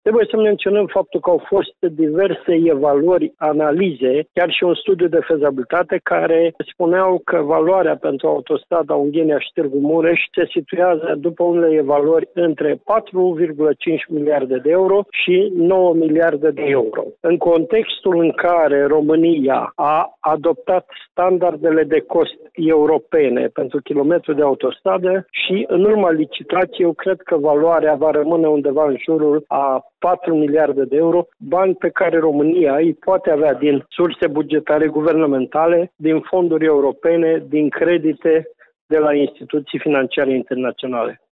Petru Movilă a declarat, pentru postul nostru de radio, că acest proiect va fi readus şi în atenţia membrilor Guvernului, care vor fi prezenţi la Iaşi, la sfârşitul lunii noiembrie, pentru celebrarea Centenarului Marii Uniri: